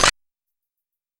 CLAP II.wav